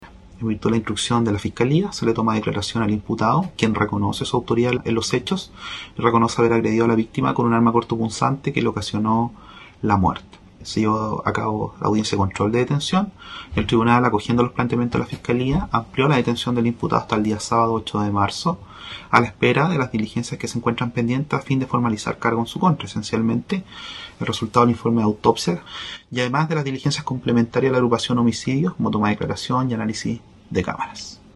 07-FISCAL-QUELLON-AMPLIAN-DETENCION-2.mp3